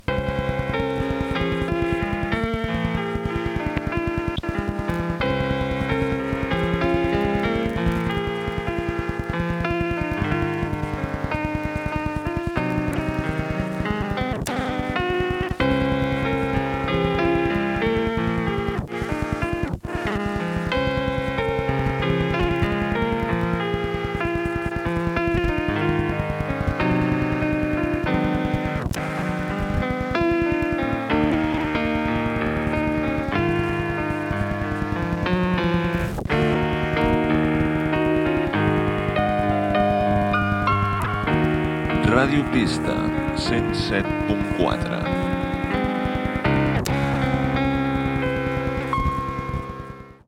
Sintonia de l'emissora i identificació
Reproducció defectuosa.